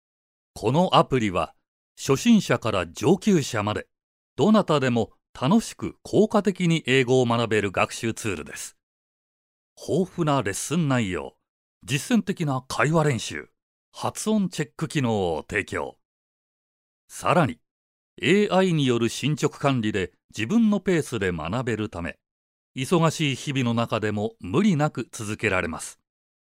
落ち着いた／穏やか
知的／クール
アルト／重厚感
どことなく和を感じさせる低音。
ボイスサンプル2（サービス紹介） [↓DOWNLOAD]